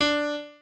pianoadrib1_2.ogg